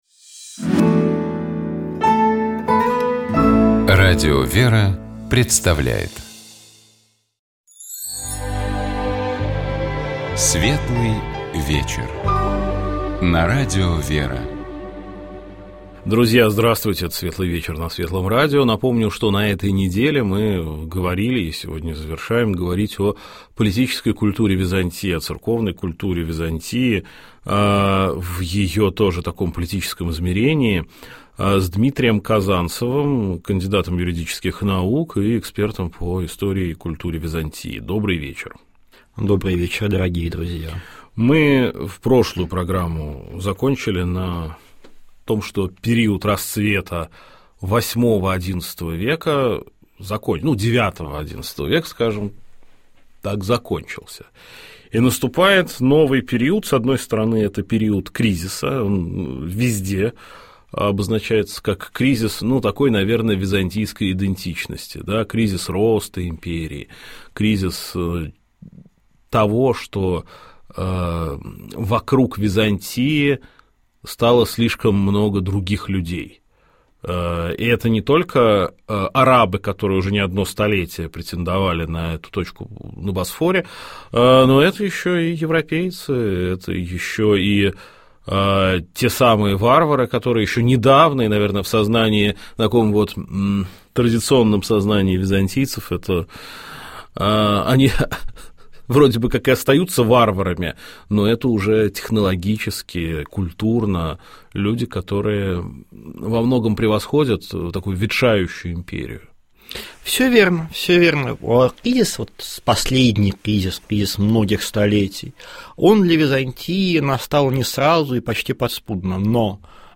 У нас в гостях был режиссер, актер, продюсер